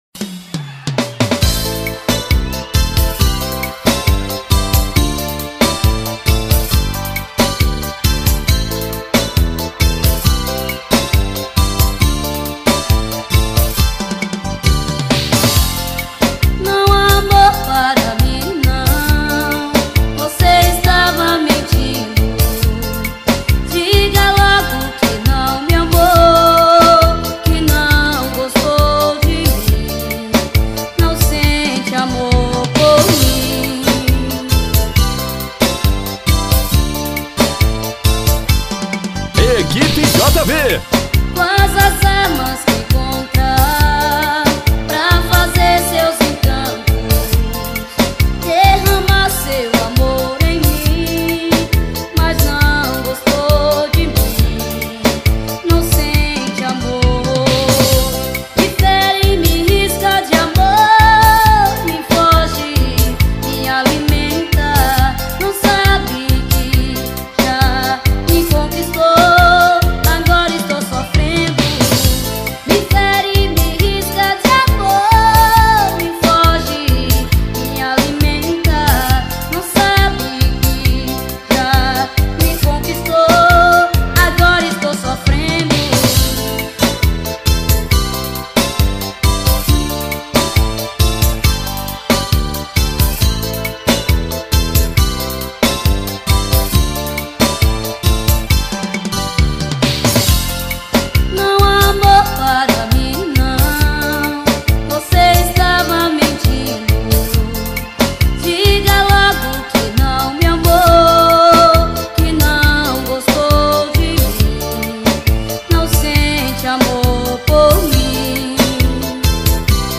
2024-11-06 15:39:47 Gênero: Forró Views